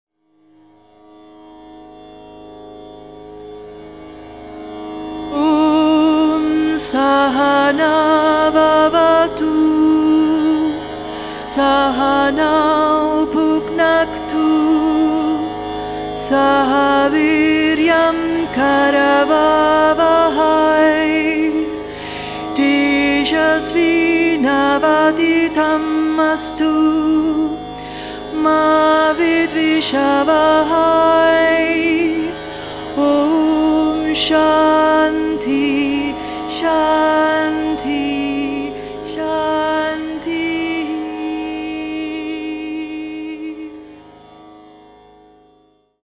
una raccolta luminosa di mantra e bhajan sacri
Con una voce dolce e potente al tempo stesso